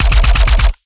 GUNAuto_RPU1 C Burst_03_SFRMS_SCIWPNS.wav